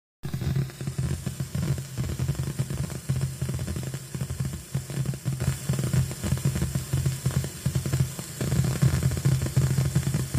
Native Instruments Komplete Audio 6 - Störgeräusche auf Output 3/4
Das Rauschen ist auf 3/4 höher und es ist so 'ne Art Windgeräusch oder Prasseln zu hören.
Die tieffrequente Störgeräuschen kommen nicht aus dem Interface, die sind durch die Mikrofonaufnahme mit dem Smartphone entstanden. Also ich meine nur das Prasseln und Rauschen.